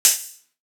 Open Hats
Phase Sizzle.wav